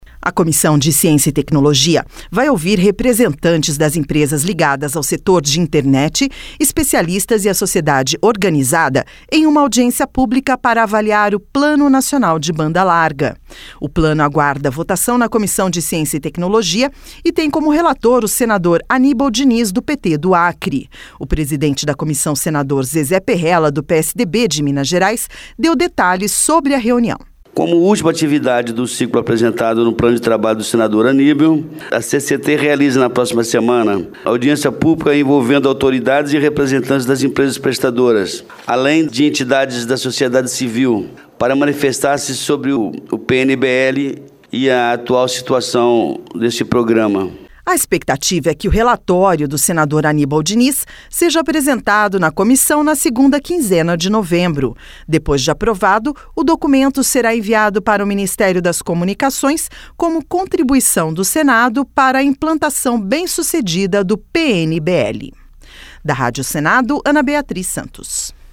O presidente da comissão, senador Zezé Perrella, do PSDB de Minas Gerais, deu detalhes sobre a reunião.